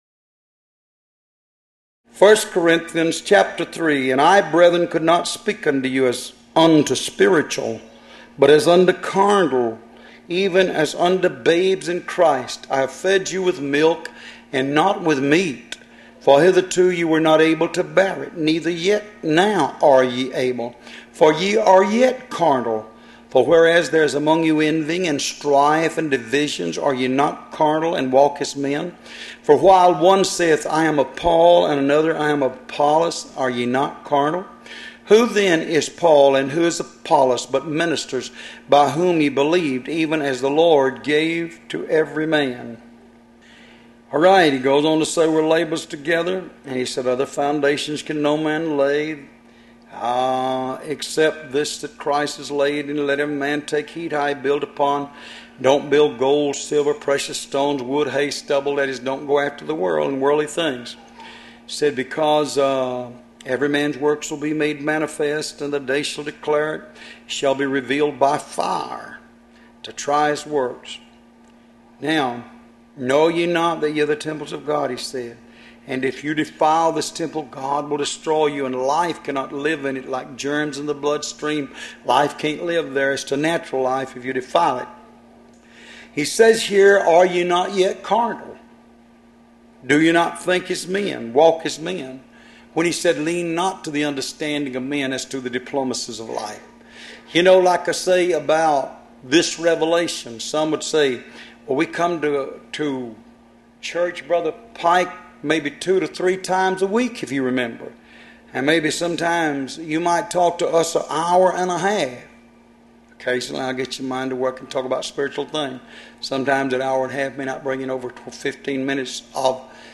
Location: Love’s Temple in Monroe, GA USA